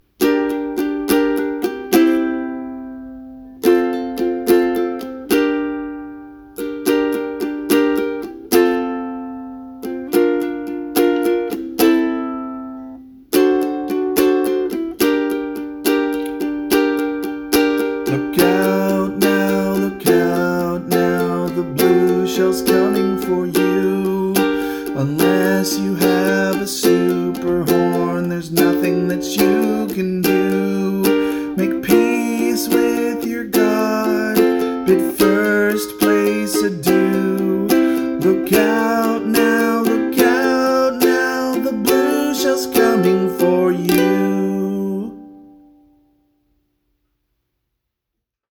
It’s in G Dorian, with some non-Dorian touches coming from the V7 chord, and the borrowing of flat-II from G’s Phrygian mode. And then it felt like the ending needed a little something extra, so I decided to land it with a Picardy third.
This is a live performance, captured with my phone.